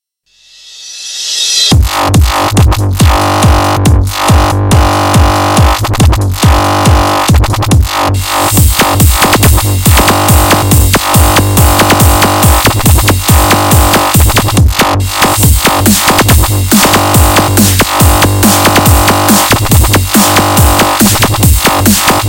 槽的例子
描述：140 BPM。以前的循环的槽。在这里获取循环的内容
Tag: 140 bpm Dubstep Loops Groove Loops 3.75 MB wav Key : Unknown